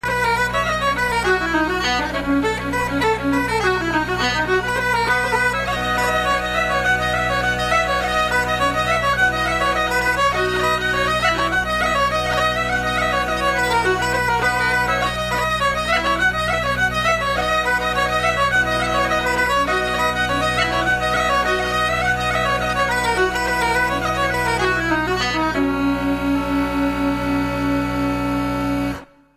Fiddle
Guitar